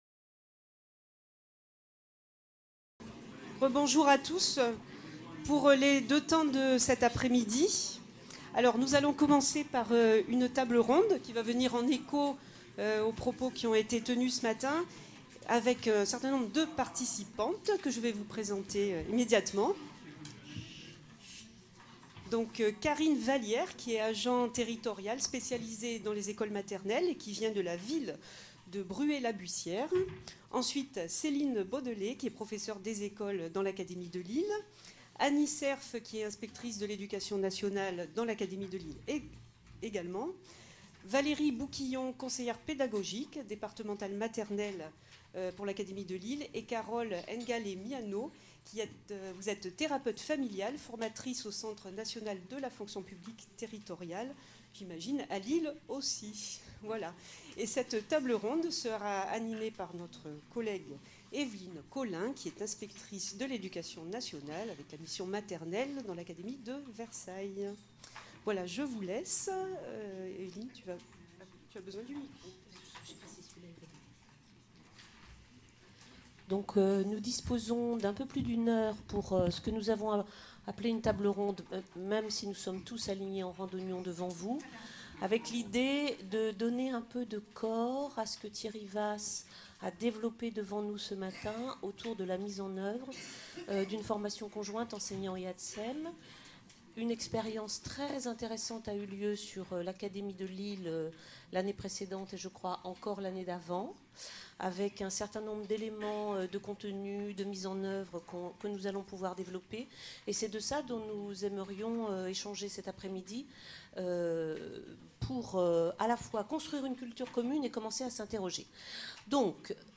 18NDGS2004 - 4 - Table ronde : Formation conjointe professeur des écoles – ATSEM | Canal U